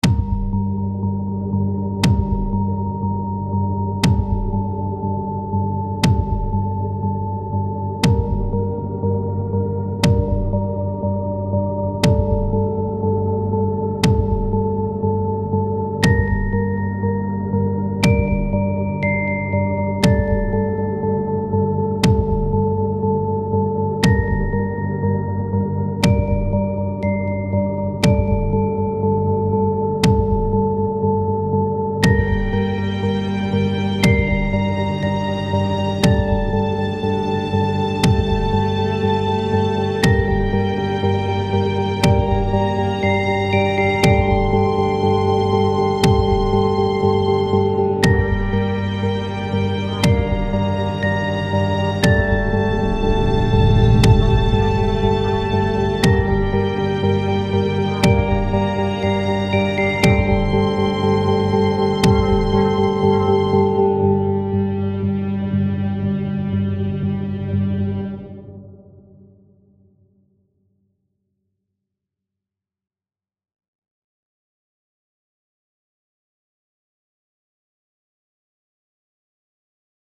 A short, mystic outro soundtrack.